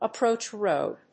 アクセントappróach ròad